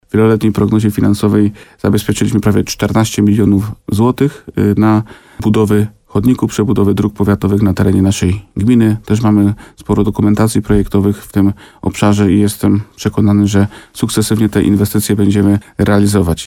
Wójt Łukowicy Bogdan Łuczkowski przypomina, że będzie to zadanie finansowane w ramach tak zwanych inwestycji samorządowych, czyli pół na pół z powiatem limanowskim.